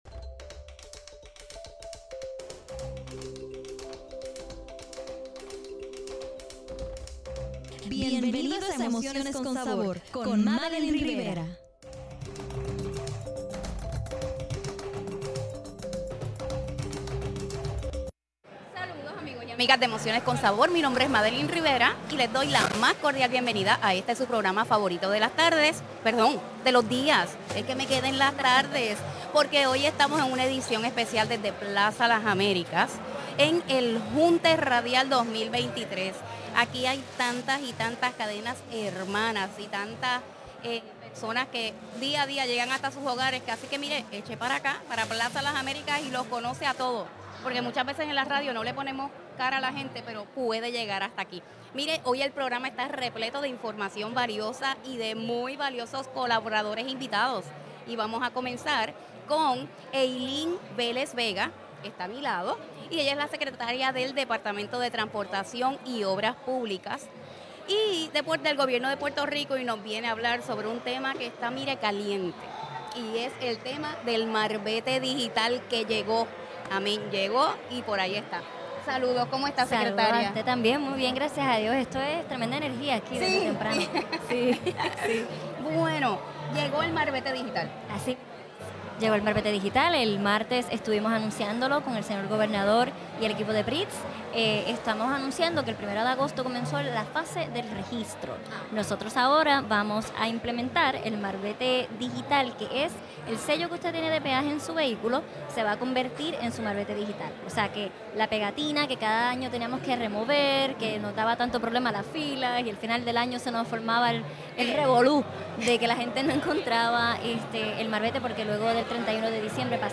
Hoy en Emociones con Sabor estaremos transmitiendo en vivo desde Plaza Las Américas gracias a la Asociación de Radiodifusores de Puerto Rico.